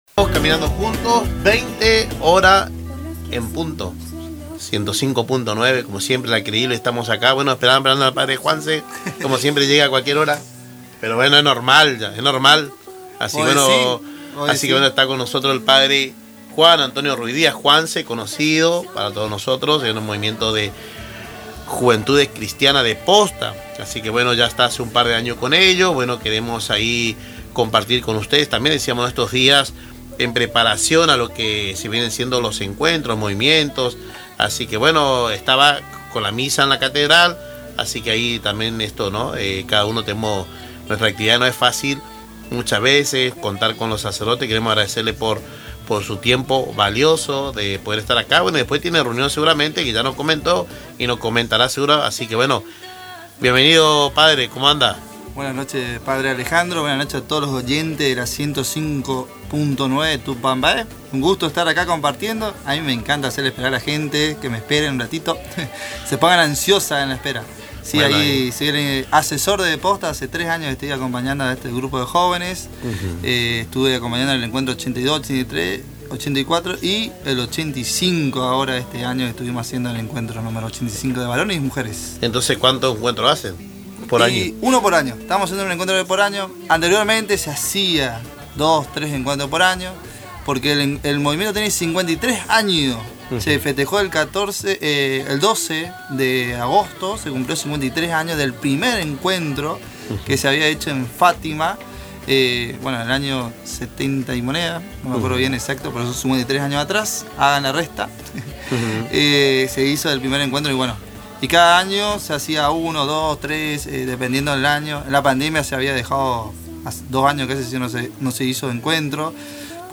En los estudios de Radio Tupambaé